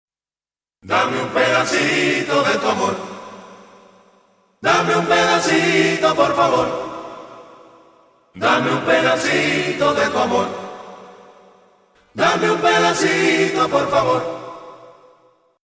Žánr: World music/Ethno/Folk
Súčasná kubánska popová interpretácie salsy